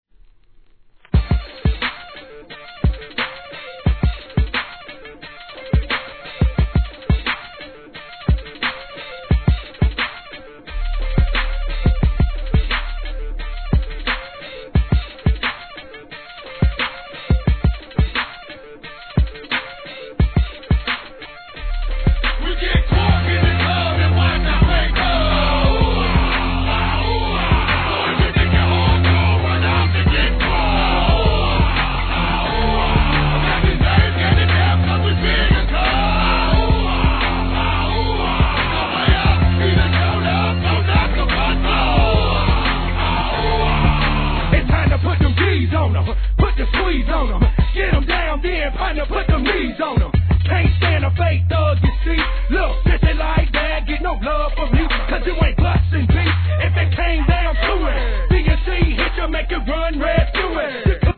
HIP HOP/R&B
これが1998年と思うと先取りのサウンドでした!